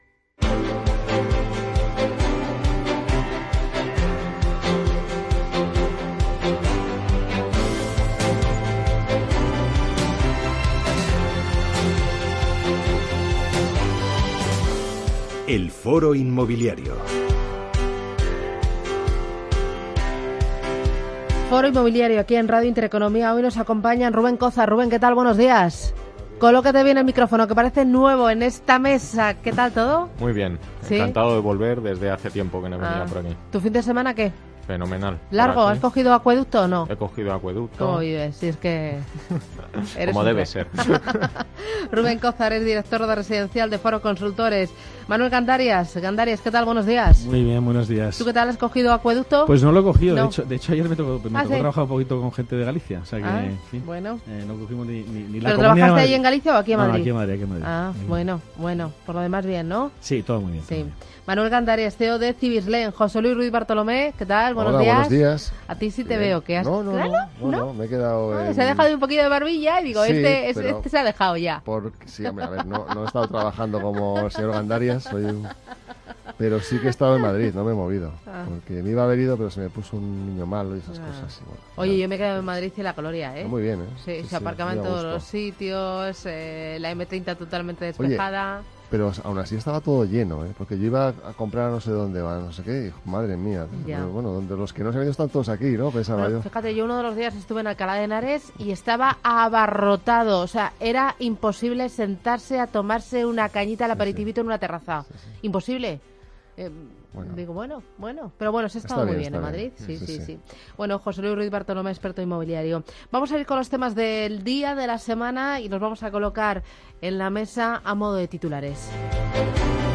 Imagen de Gesvieco Todos estos temas se han tratado en el programa Foro Inmobiliario de Intereconomía Radio con expertos del sector.